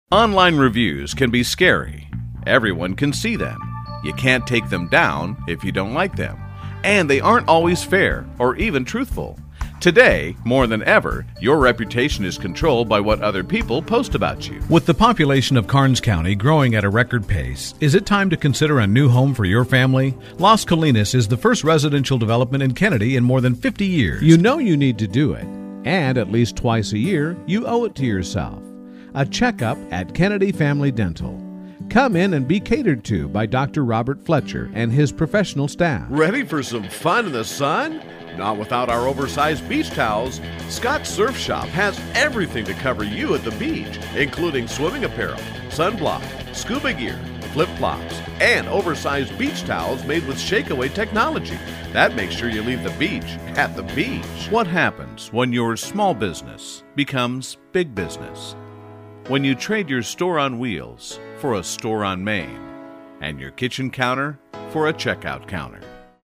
Commercial Demo
English - Midwestern U.S. English
Middle Aged
Commercial Demo High Quality.mp3